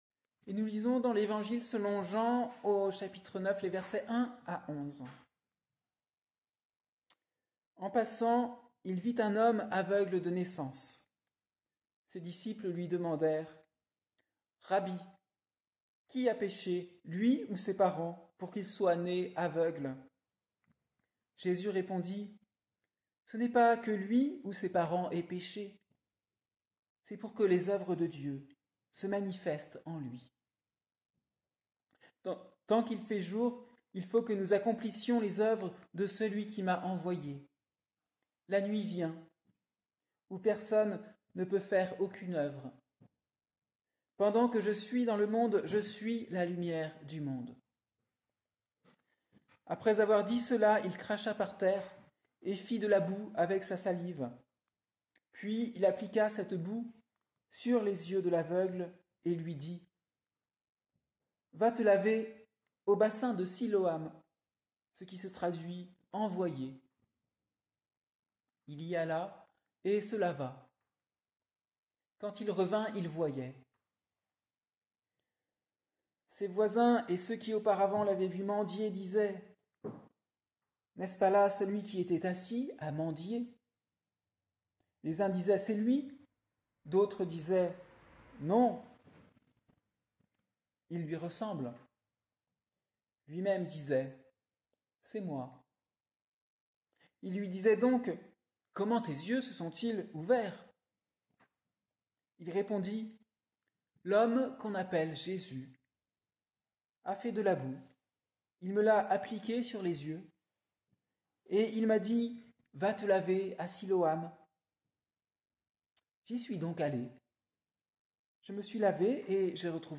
Prédication du dimanche 6 octobre 2024 à Yssingeaux (donnée pour la première fois le 18 mars 2023 à Barbezieux).